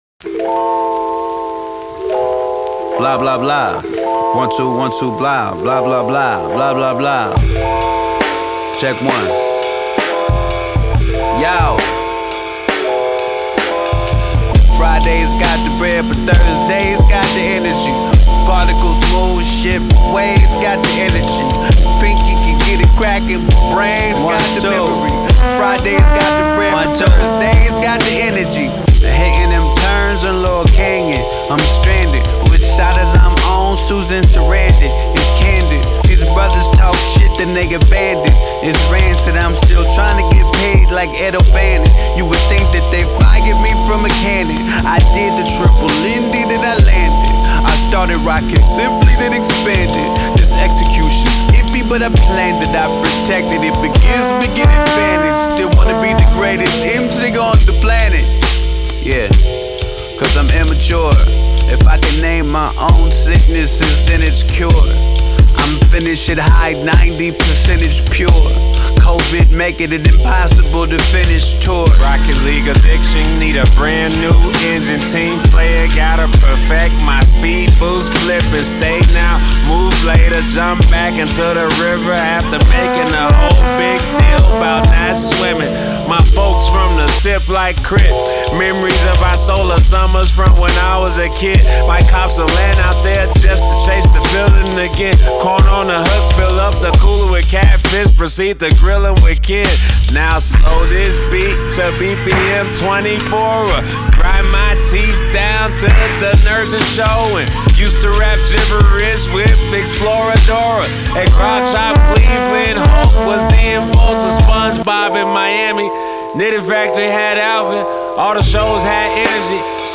Astonishing American hip-hop artist and comedian